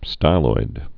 (stīloid)